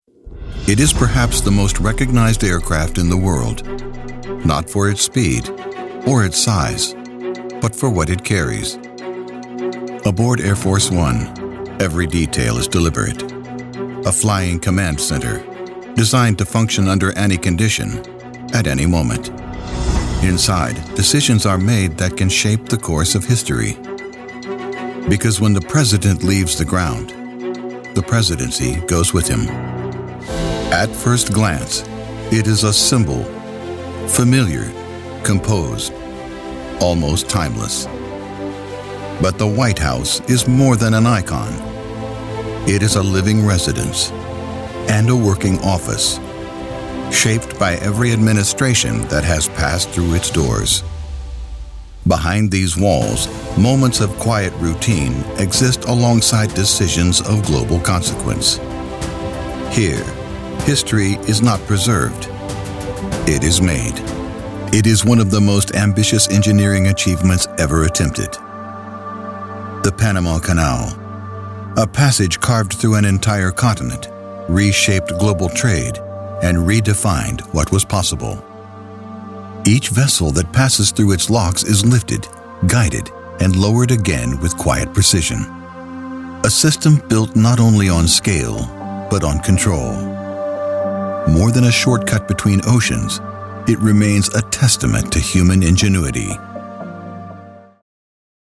American Voice Over Talent
Adult (30-50) | Older Sound (50+)
0424NatGeo_Documentary_Reel.mp3